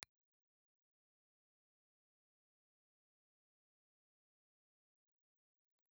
Cardioid
Impulse Response File:
Impulse Response file of Magneta dynamic microphone.